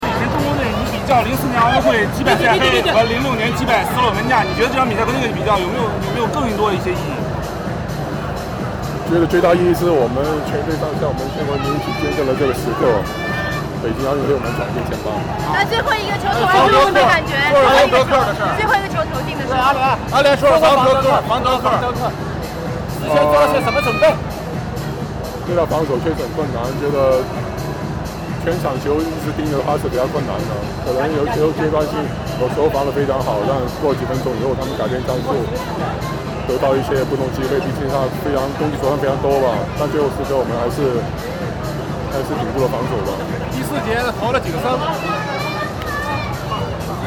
赛后，易建联在混合区接受了媒体的采访——
易建联采访音频MP3